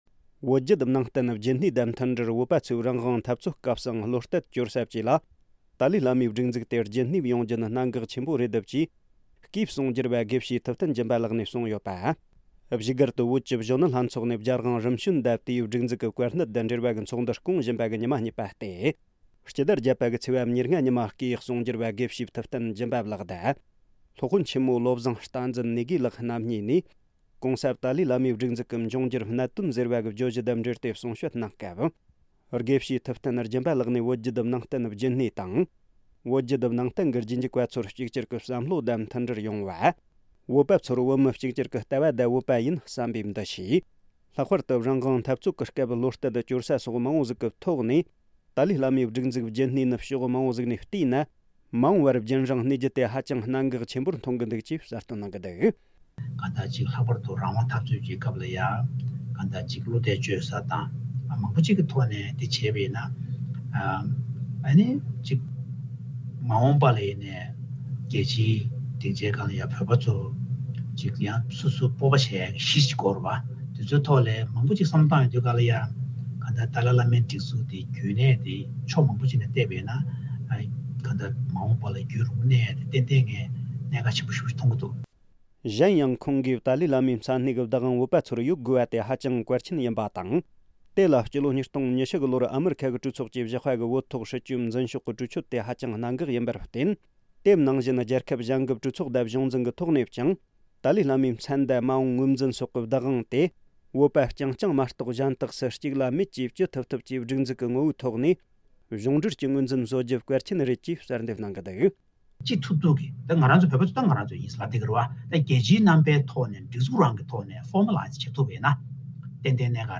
ཏཱ་ལའི་བླ་མའི་སྒྲིག་འཛུགས་རྒྱུན་གནས་དགོས་རྒྱུ་གལ་ཆེན་པོ་ཡིན། སྒྲ་ལྡན་གསར་འགྱུར།